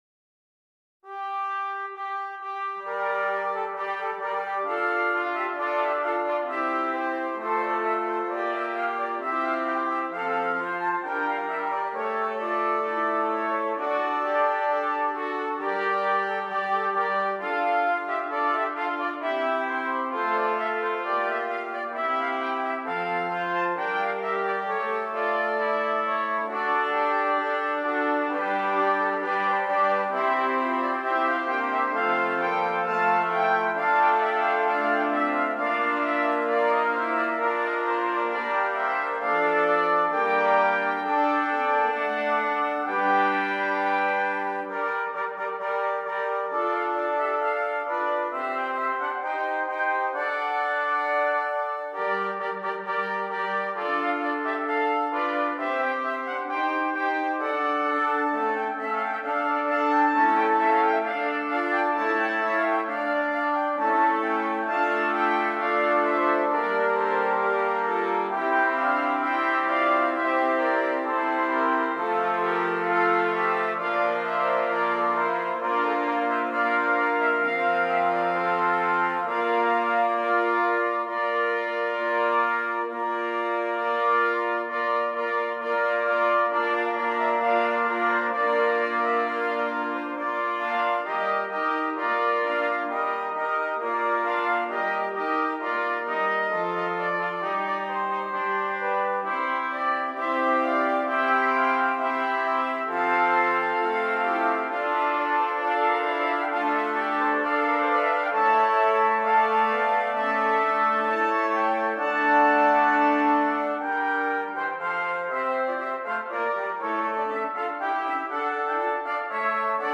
8 Trumpets
This arrangement is for 8 trumpets.